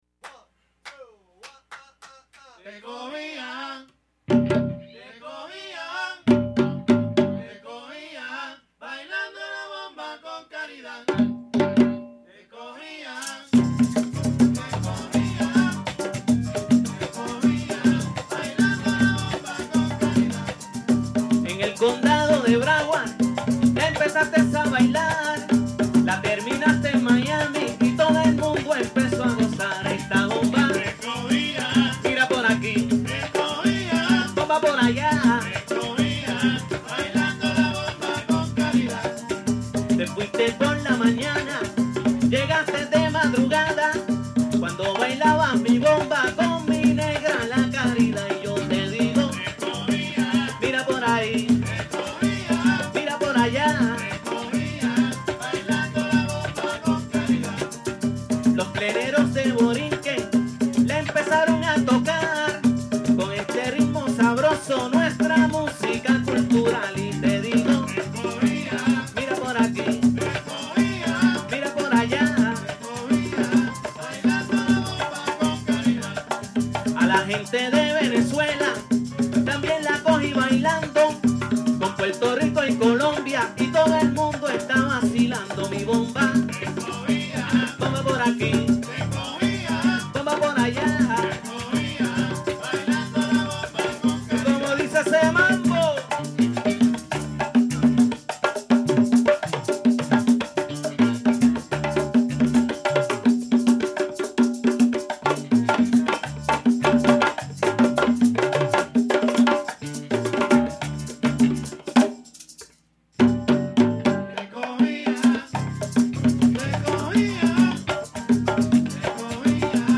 This is a bomba
vocals, maracas, shakers, güiro
keyboard